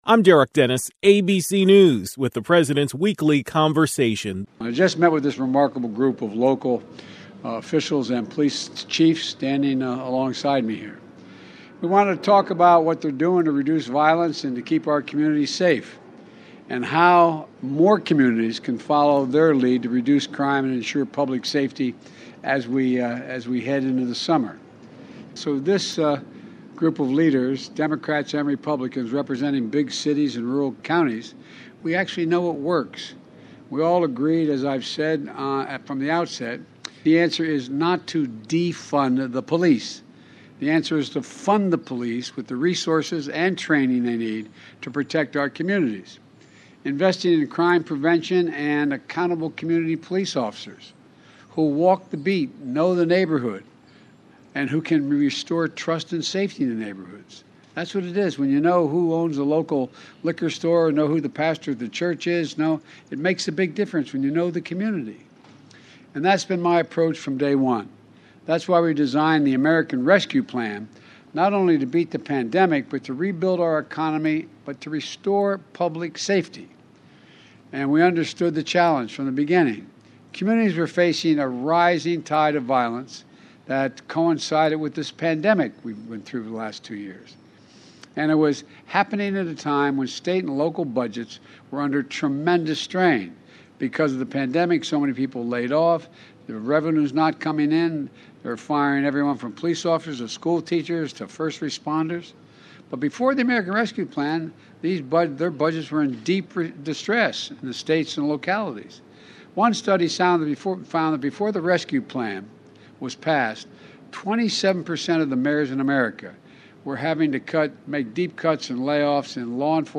President Biden delivered a speech on Police Reform.